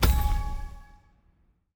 Special Click 05.wav